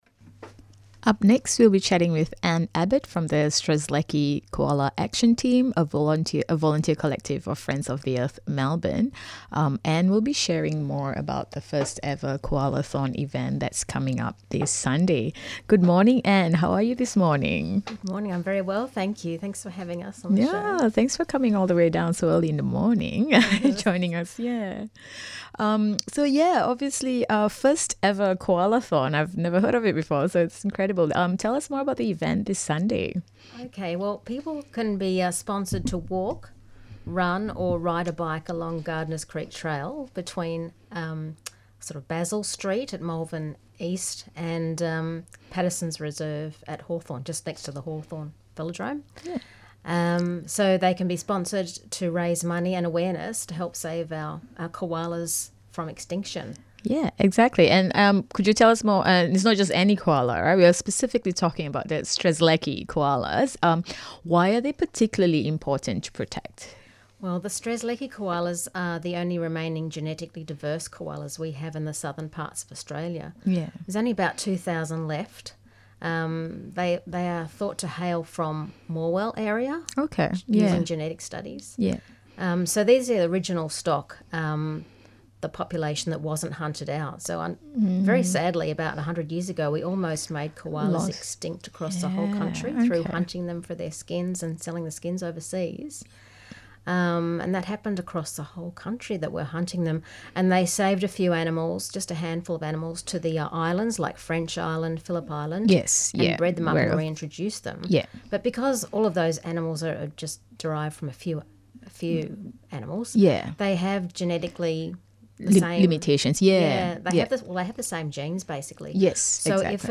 Current affairs, media analysis, alternative media.